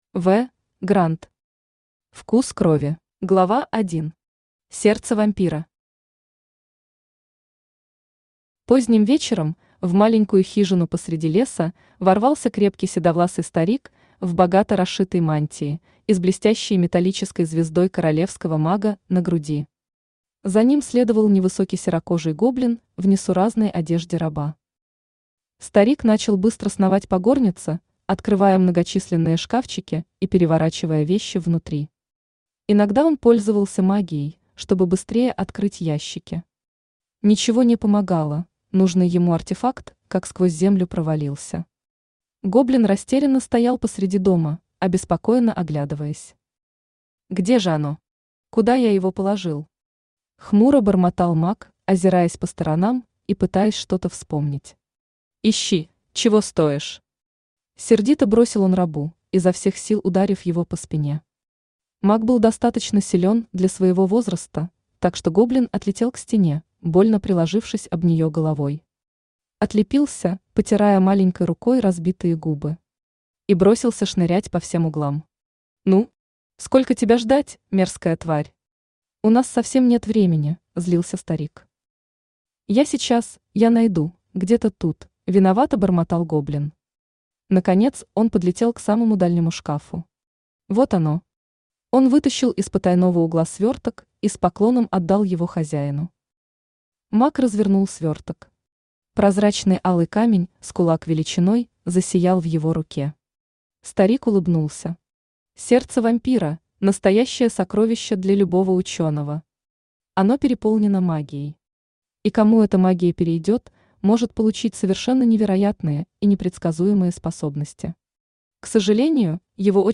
Аудиокнига Вкус крови | Библиотека аудиокниг
Aудиокнига Вкус крови Автор В. Гранд Читает аудиокнигу Авточтец ЛитРес.